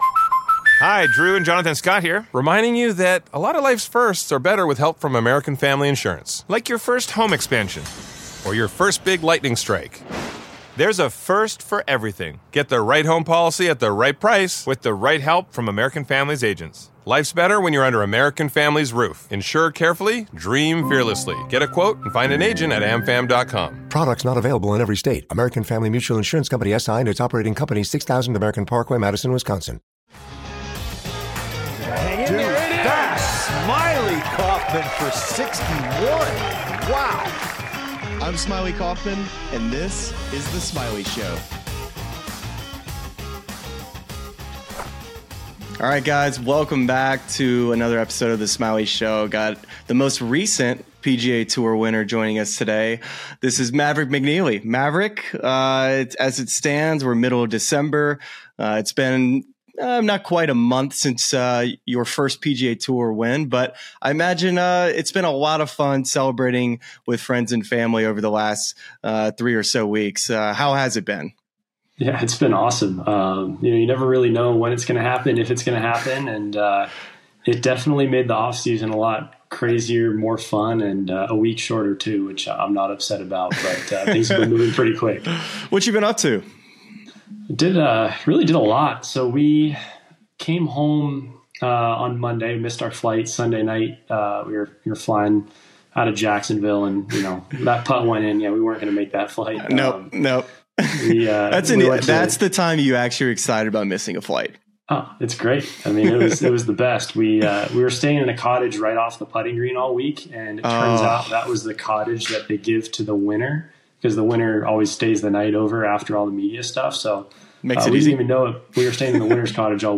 Don't miss this candid conversation with one of golf's rising stars.